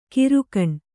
♪ kirukaṇ